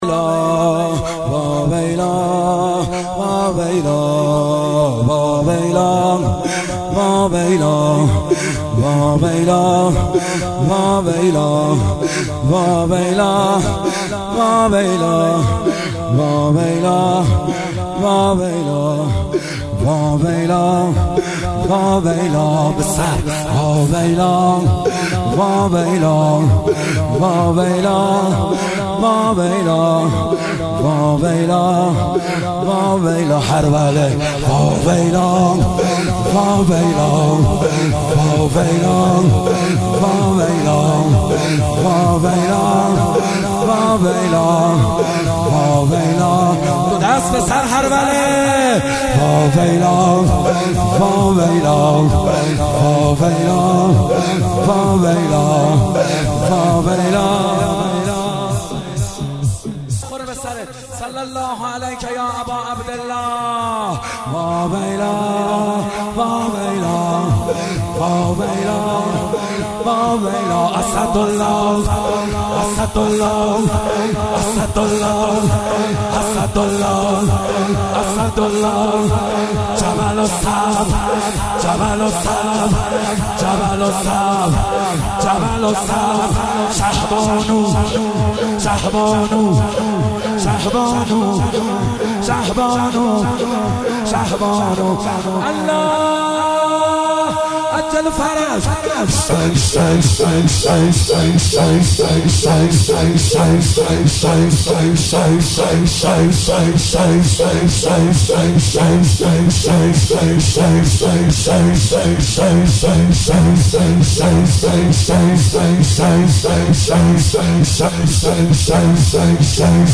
شور(ذکر)